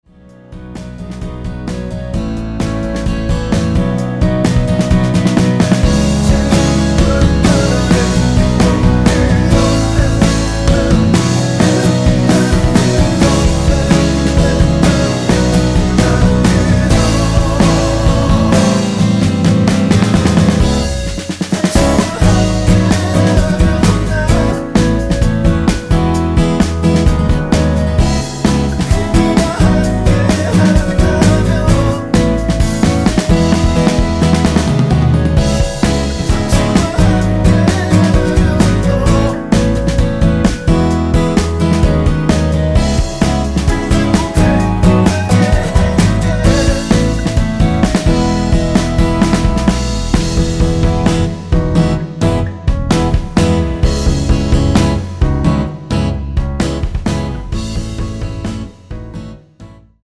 전주 없이 시작하는 곡이라 4박 카운트 넣어 놓았습니다.(원키 미리듣기 확인)
원키에서(-1)내린 멜로디와 코러스 포함된 MR입니다.
앞부분30초, 뒷부분30초씩 편집해서 올려 드리고 있습니다.
중간에 음이 끈어지고 다시 나오는 이유는